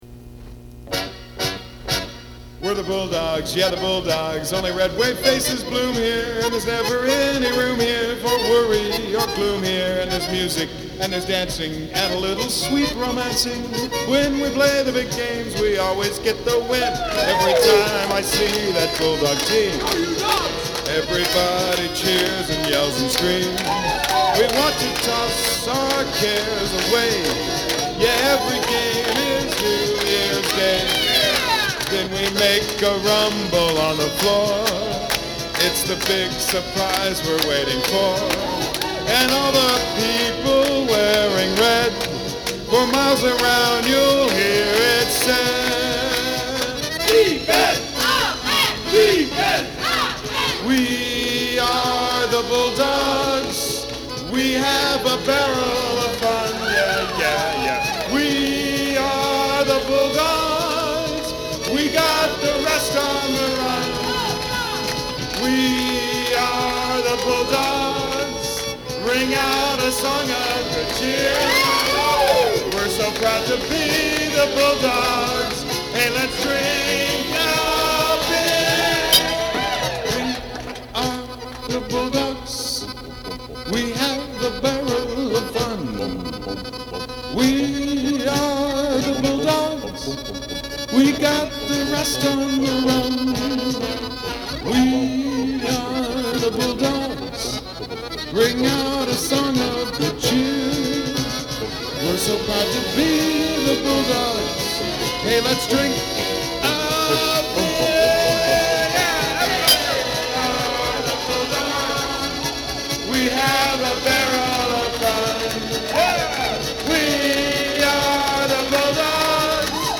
a cheesy reworking of
If you’re a fan of bad novelty records